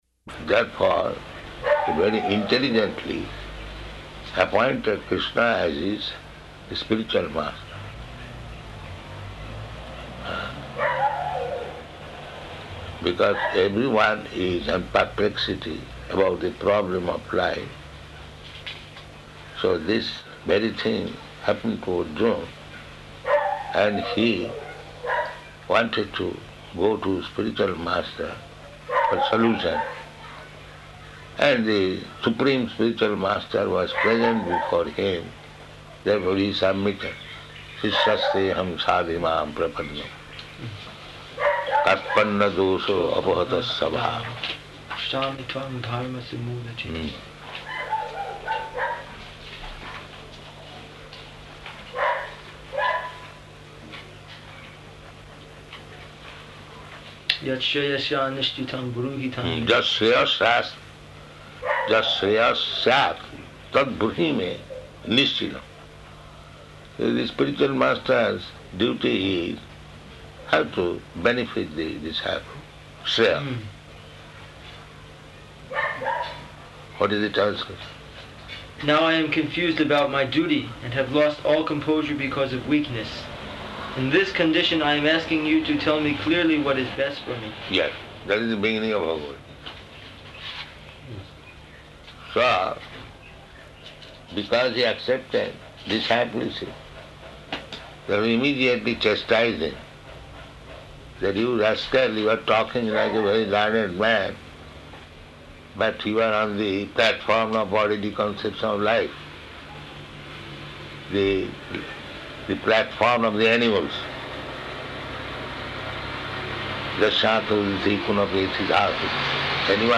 Room Conversation
-- Type: Conversation Dated: April 30th 1976 Location: Fiji Audio file